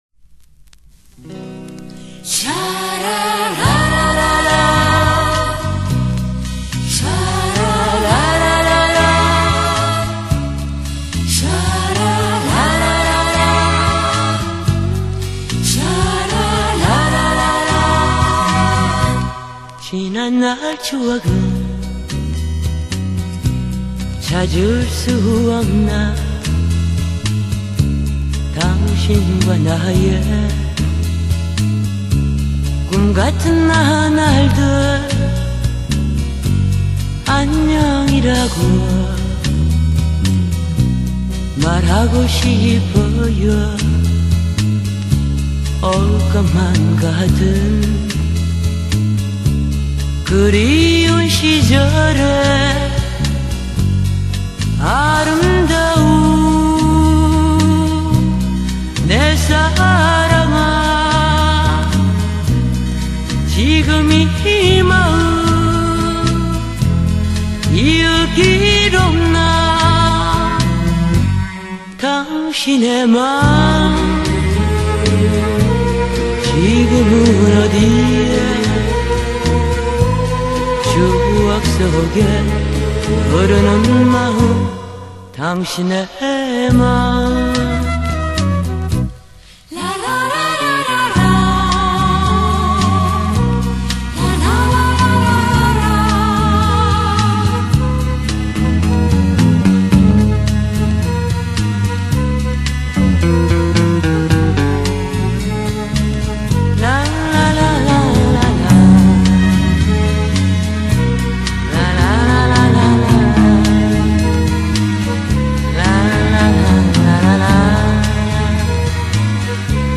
샹송을 번안한 곡이 많은 것 같습니다.